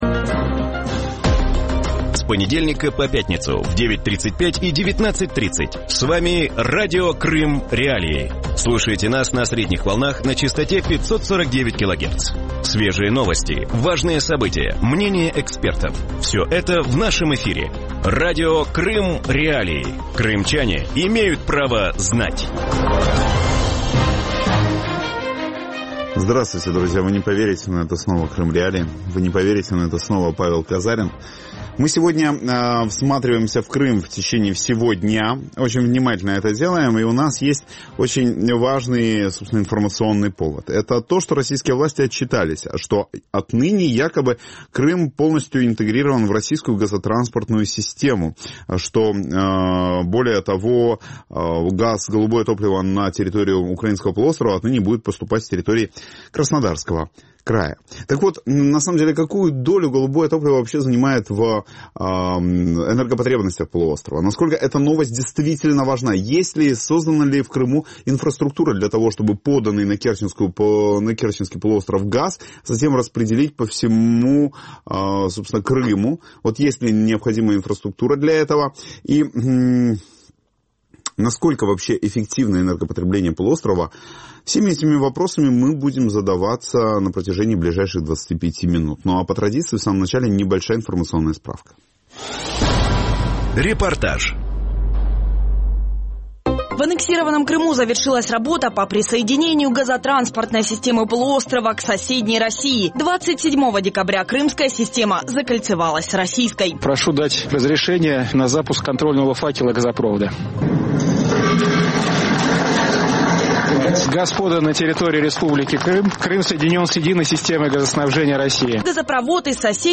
В вечернем эфире Радио Крым.Реалии обсуждают запуск газопровода из Краснодарского края в Крым. На какую мощность рассчитана система, сколько газа потребит Крым и как новый газопровод повлияет на жизнь крымчан?